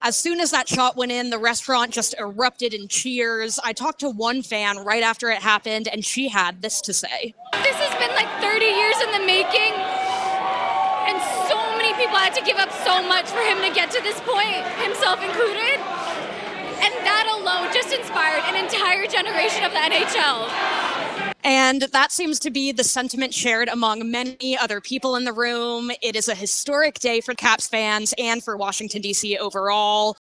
The Washington Capitals hosted a Gr8 Chase Watch Party at Whitlow’s at the Wharf on Sunday.
speaks to Capitals fans at The Wharf about their reaction to Alex Ovechkin's record-breaking goal.